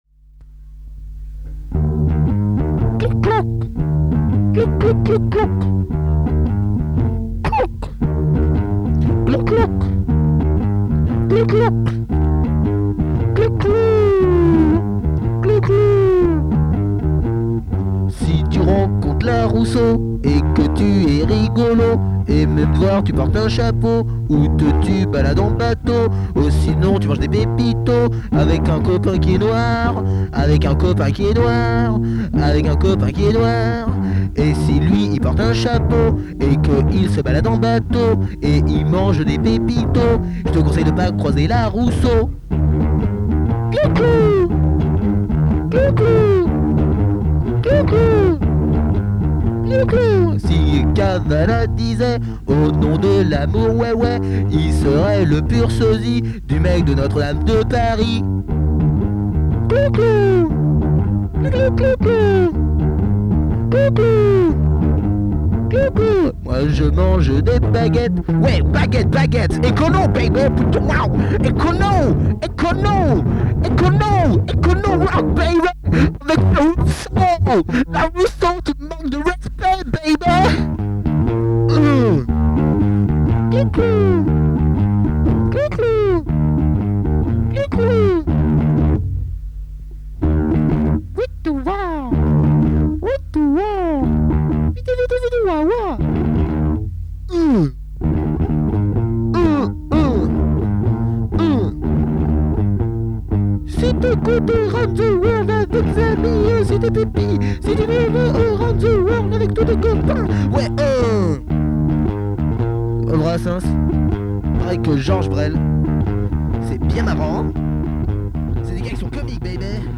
disque entiérement composé et mal  enregistré devant la télé